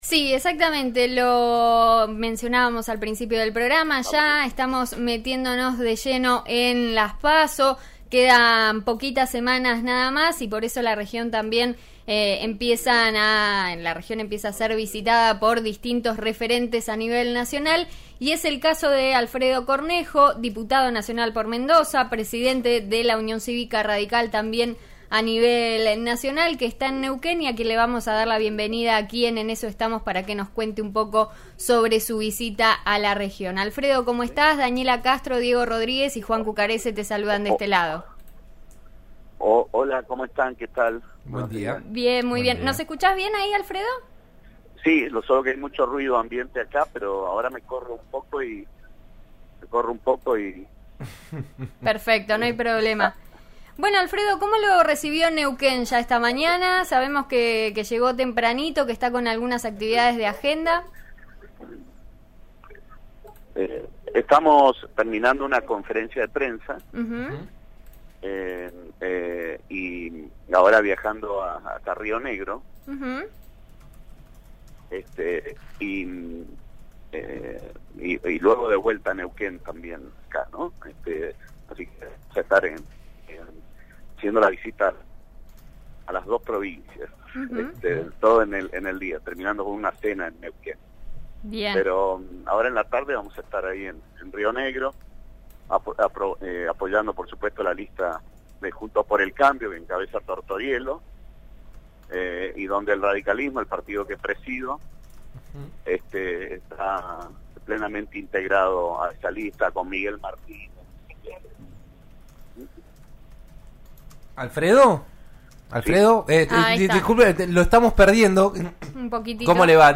En eso estamos de RN RADIO 89.3 dialogó con Alfredo Cornejo, diputado nacional por Mendoza y presidente de la Unión Cívica Radical, durante su visita a Río Negro y Neuquén junto a otros referentes de Juntos por el Cambio.